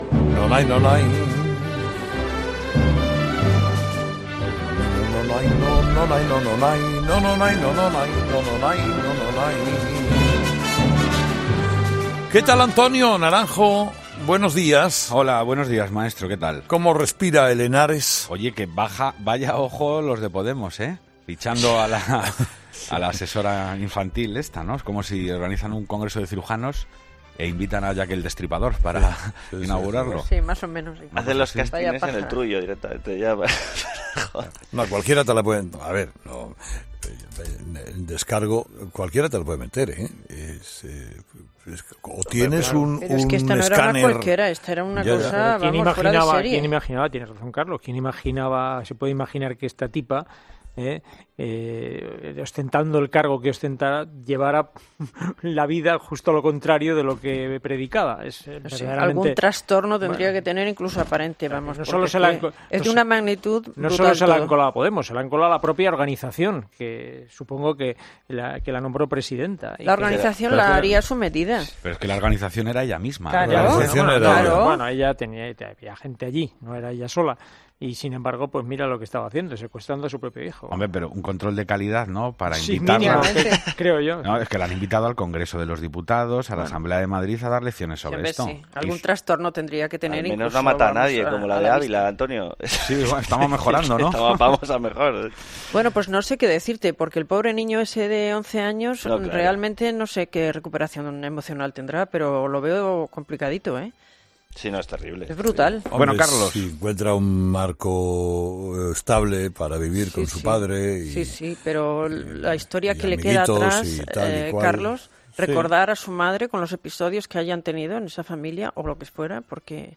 La Tertulia de los Oyentes es el espacio que Herrera da a los oyentes para que opinen sobre los temas de actualidad.